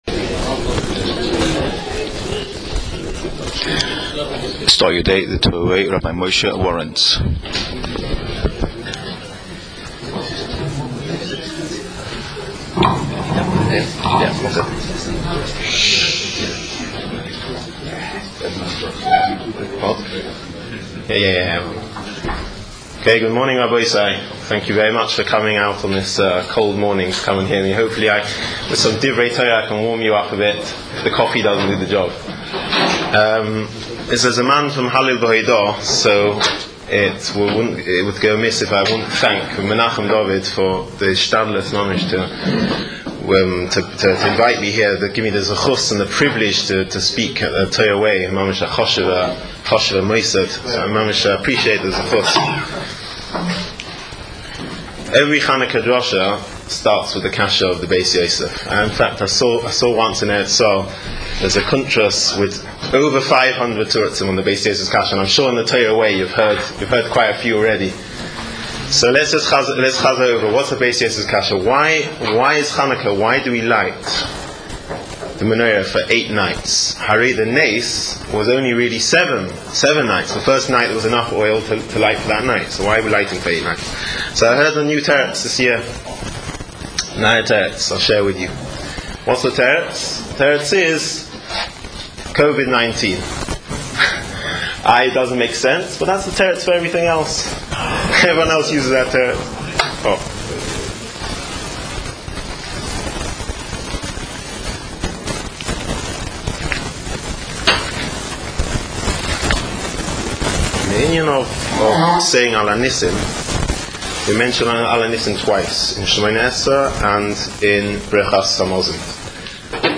Start Your Day The TorahWay Manchester provides daily shiurim on a wide range of topics.